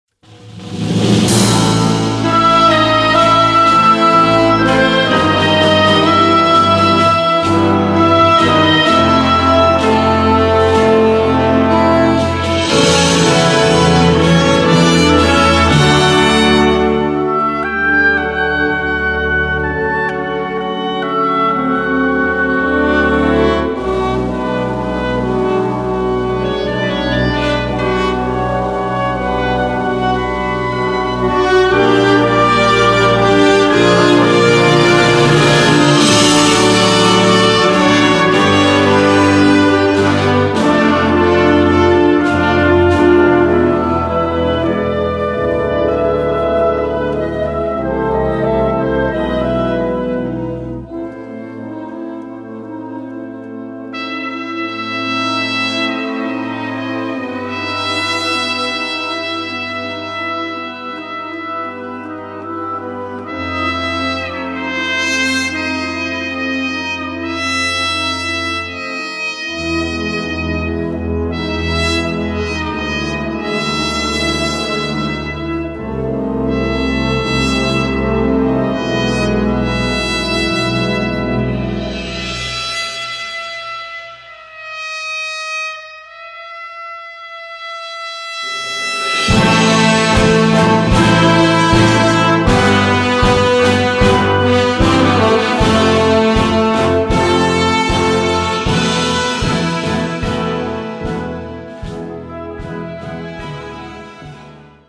Gattung: Filmmusik mit opt. Sopran
A4 Besetzung: Blasorchester PDF